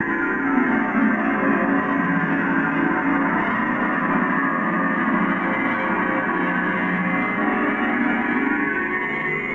snd_dtrans_drone.ogg